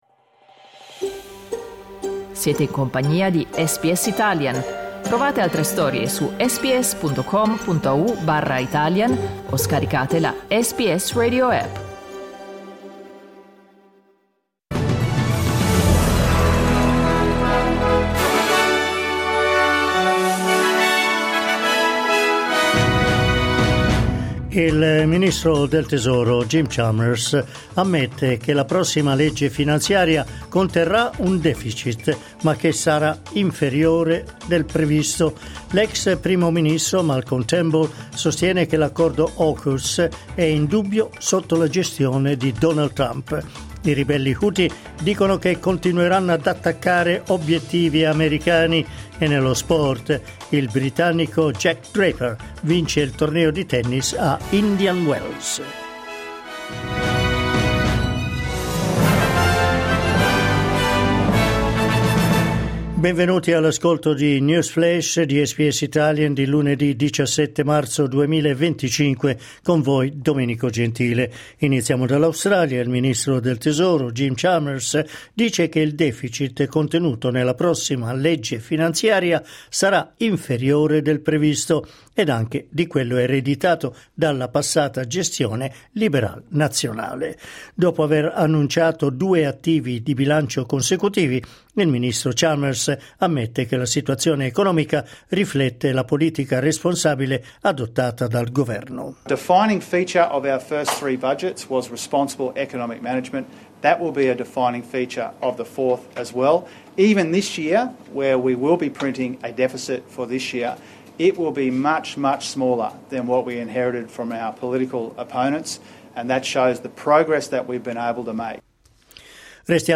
News flash lunedì 17 marzo 2025